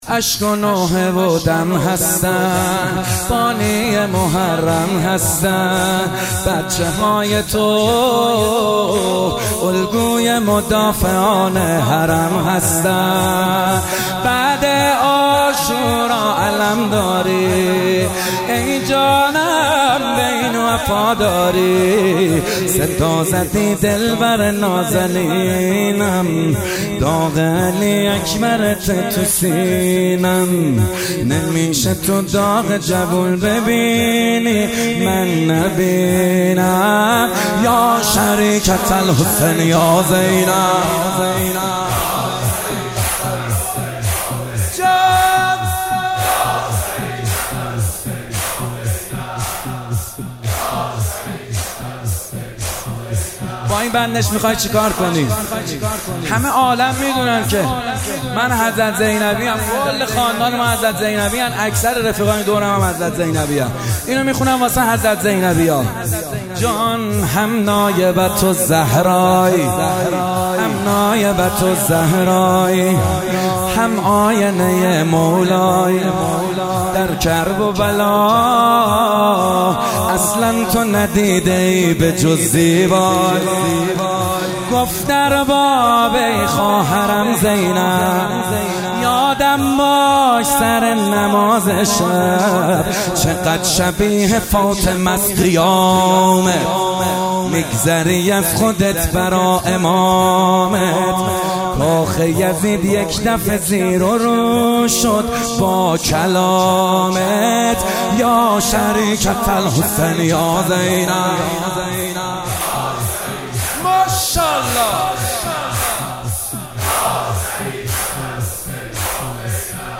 محرم 98 روز چهارم - شور - اشک و نوحه و دم هستن